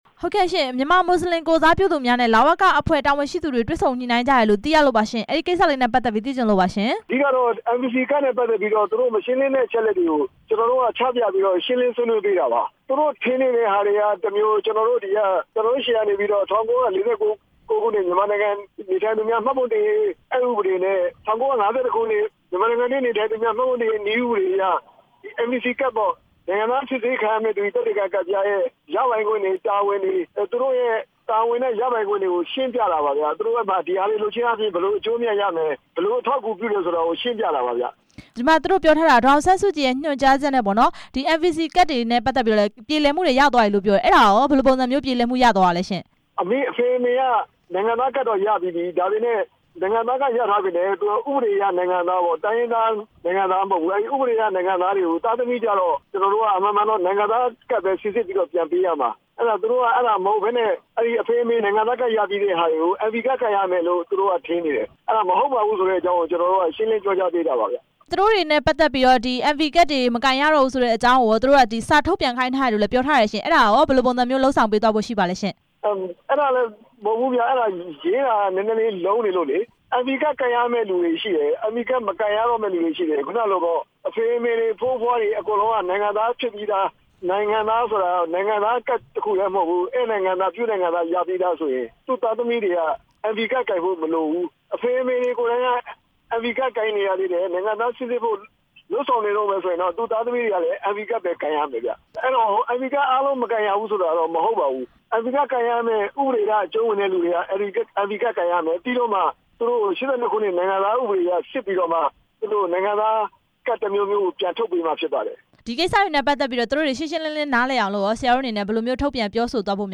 မြန်မာမွတ်စလင်တွေနဲ့ တွေ့ဆုံခဲ့တဲ့ အမြဲတမ်းအတွင်းဝန် ဦးမြင့်ကြိုင်နဲ့ မေးမြန်းချက်